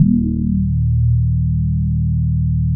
27 BASS   -R.wav